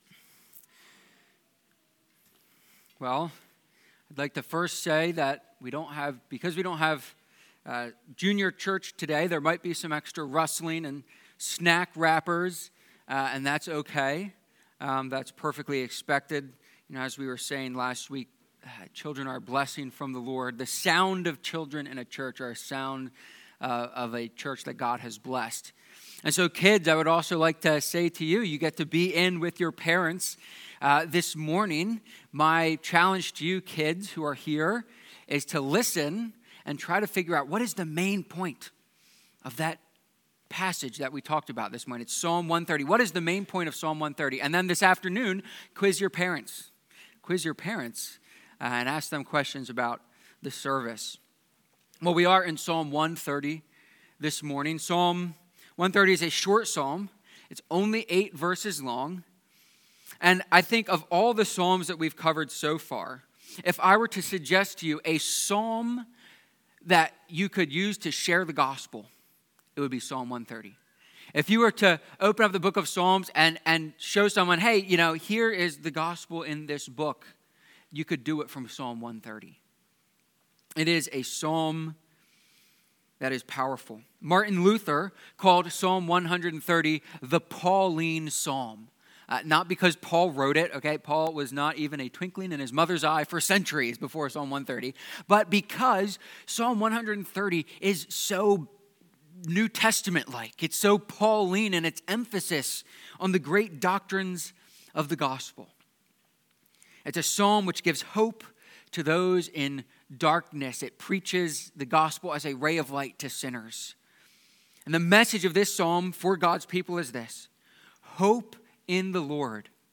Psalm-130-sermon.mp3